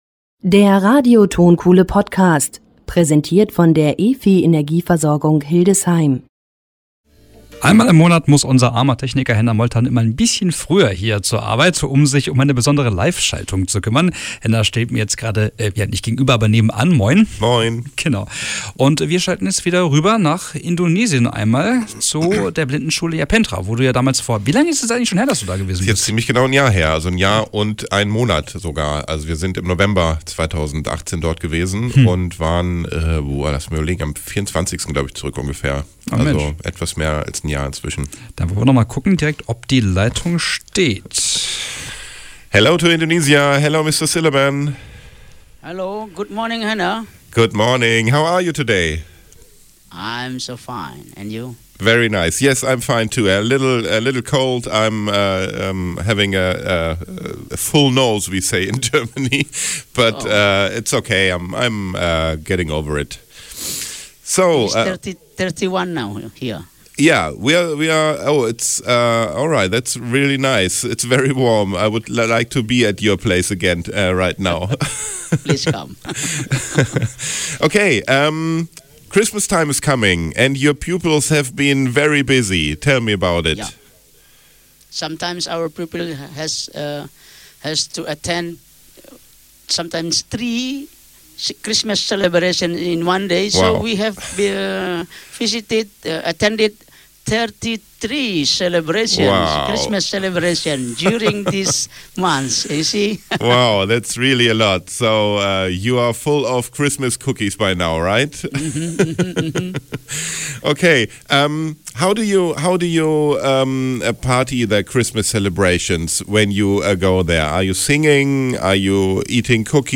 liveschaltung-nach-indonesien-kurz-vor-weihnachten.mp3